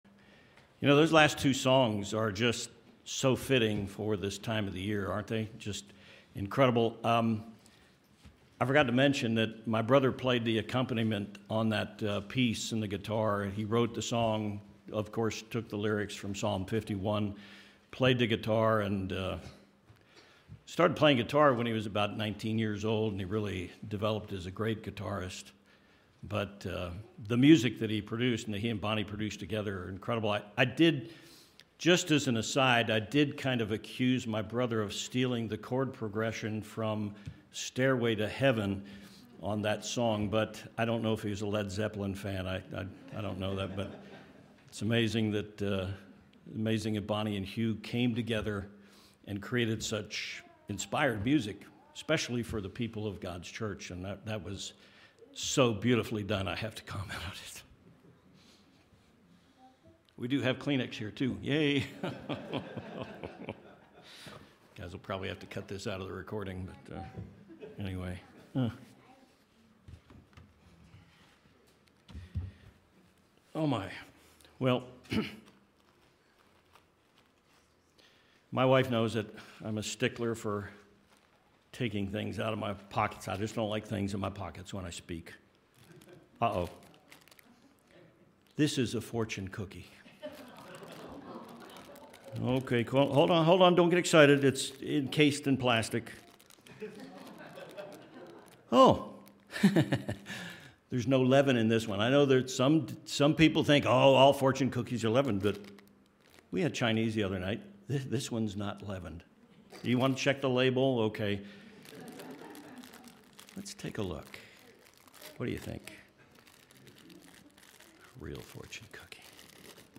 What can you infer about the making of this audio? Given in El Paso, TX Tucson, AZ